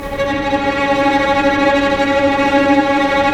Index of /90_sSampleCDs/Roland LCDP13 String Sections/STR_Vcs Tremolo/STR_Vcs Trem p